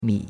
mi3.mp3